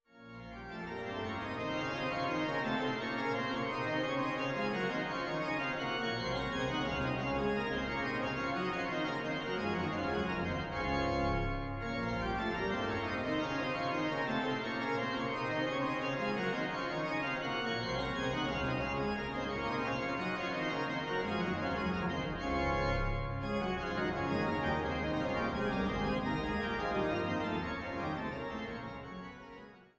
Einweihungskonzertes vom 03.11.2000